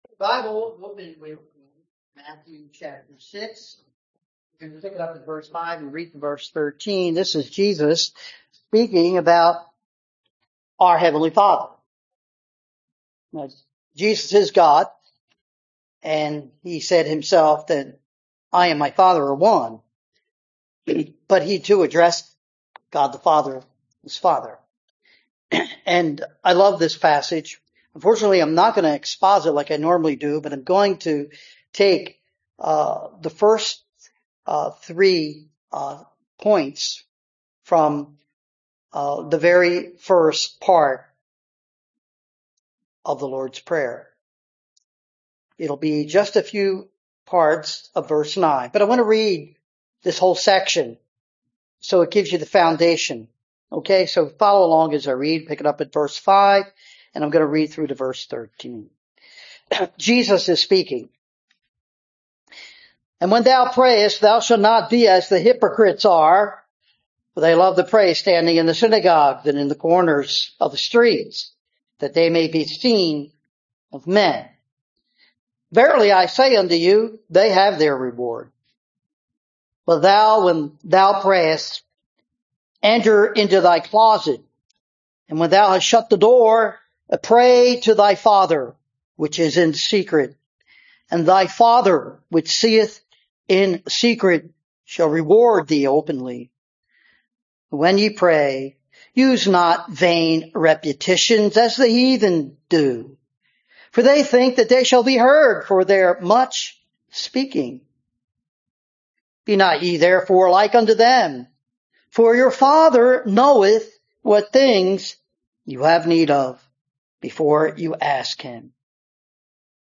Matthew 6:5-13 Service Type: Holiday Preaching Introduction